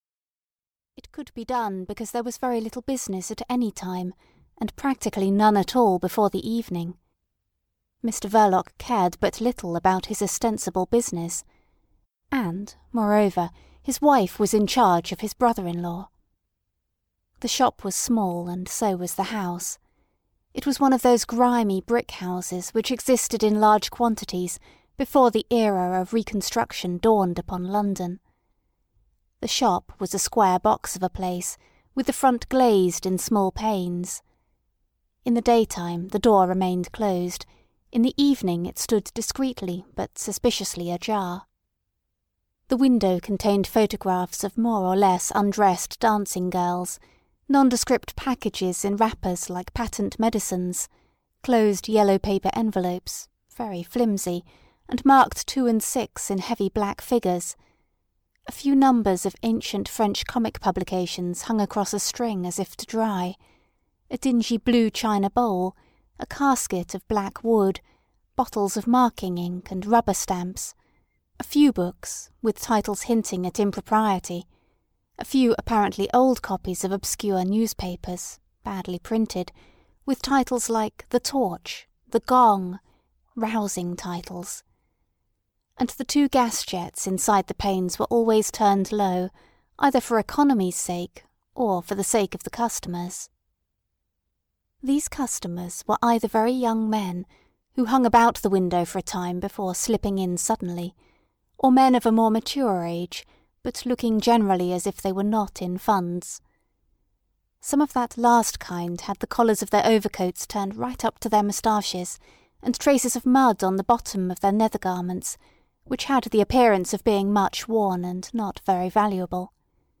The Secret Agent (EN) audiokniha
Ukázka z knihy